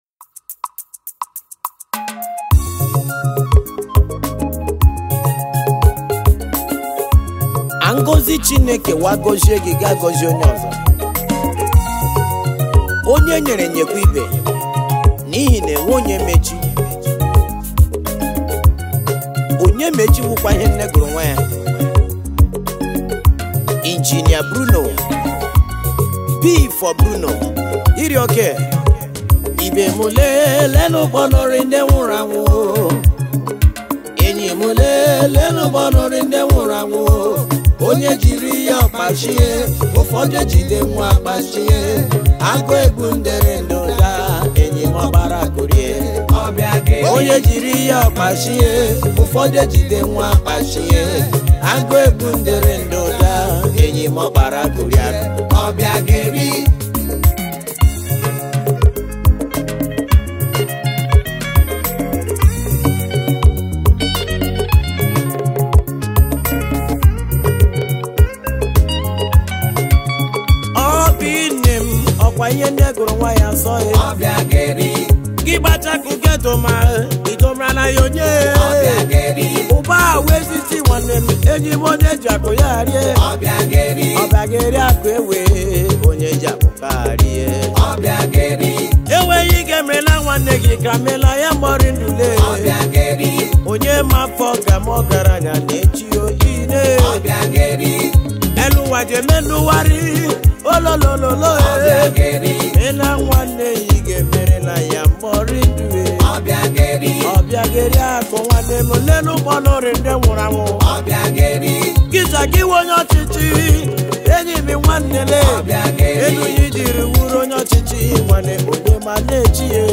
March 20, 2025 Publisher 01 Gospel 0